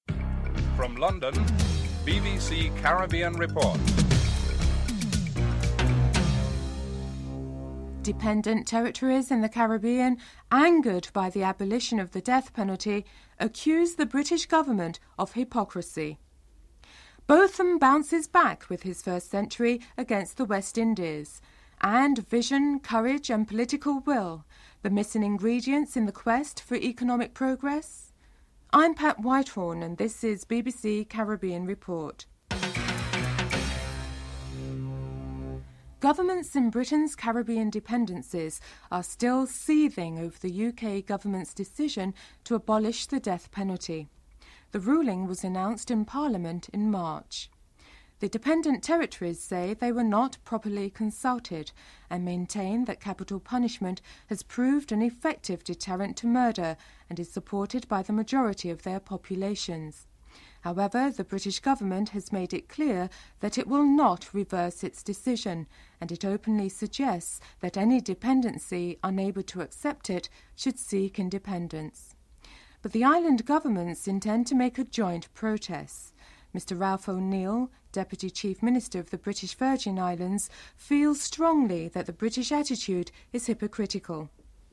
1. Headlines (00:00-00:35)
Jonathan Agnew reports on the amazing inning of Botham (12:53-14:50)